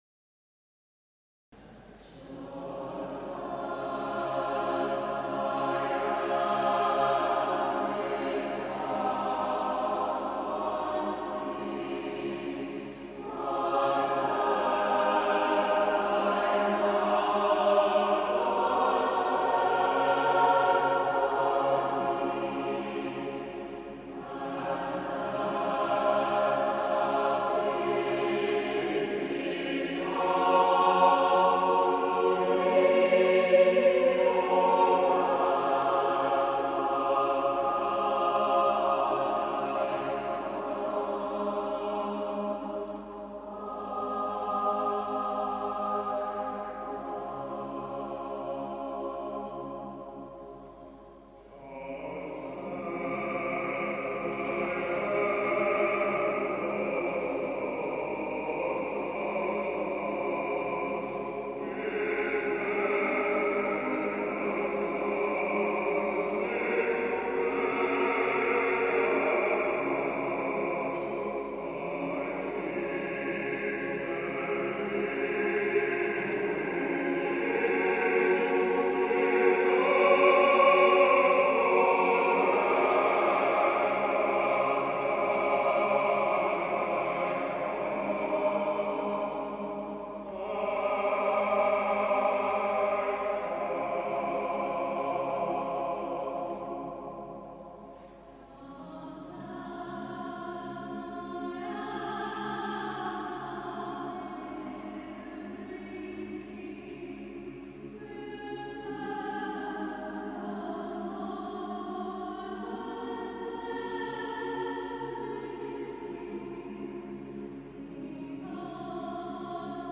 at the Riga Dom